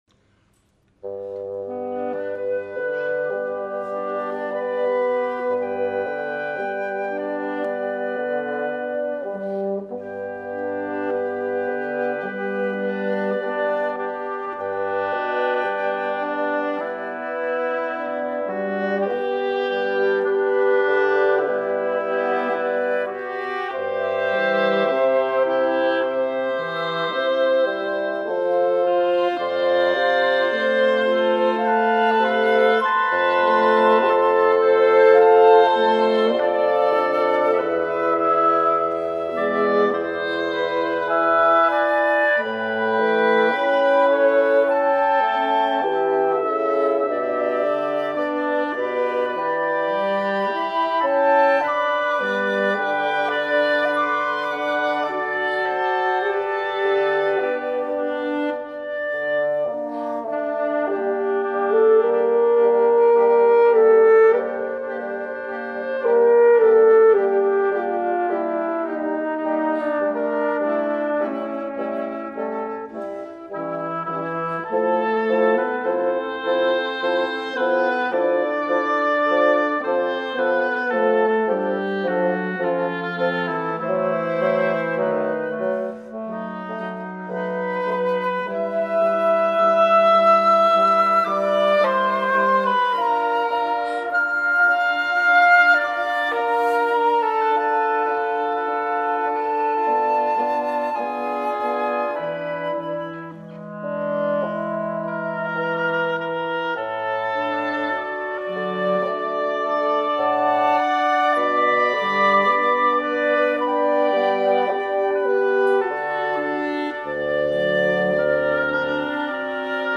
“Der Engel” (Ο άγγελος) για Κουιντέτο Ξυλίνων (live)
Kουιντέτο πνευστών
φλάουτο
όμποε
κλαρινέτο
φαγκότο
κόρνο